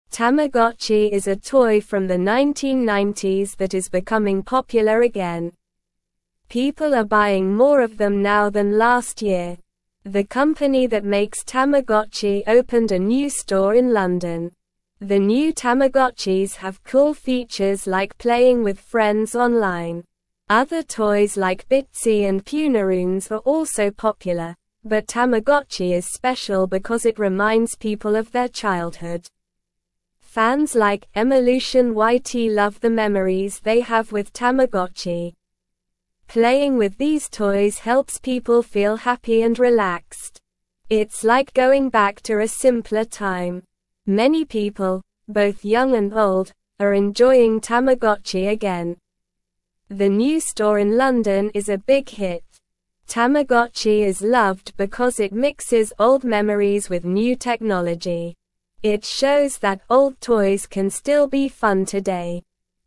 Slow
English-Newsroom-Lower-Intermediate-SLOW-Reading-Tamagotchi-Toy-Makes-People-Happy-and-Relaxed.mp3